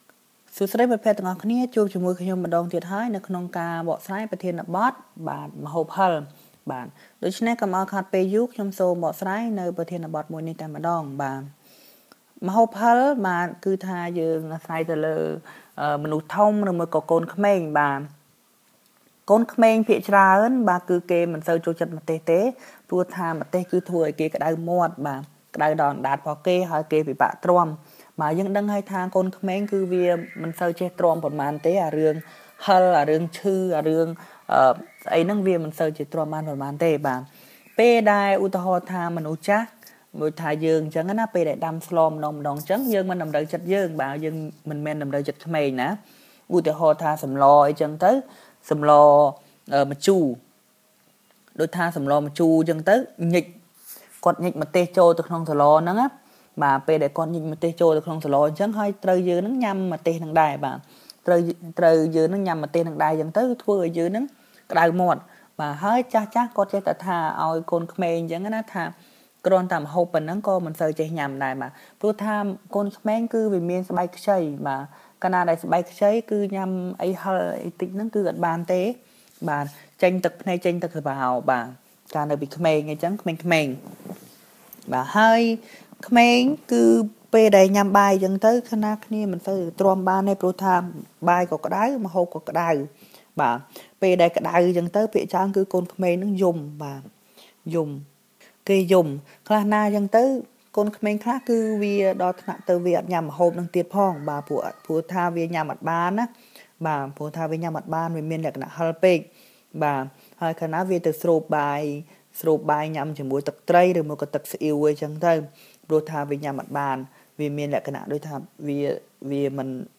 All recordings are unscripted, natural speech, 100% in Khmer, and come with a transcript.